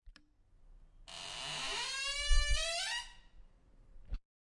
开放式衣柜2
描述：打开木制衣柜的门，旋钮的金属拨浪鼓和木材的摆动记录了Zoom H2